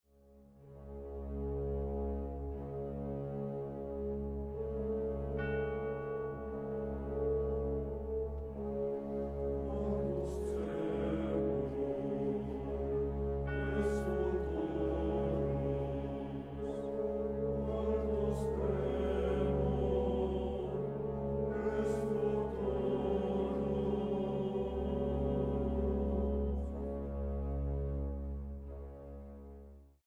mezzosoprano
tenor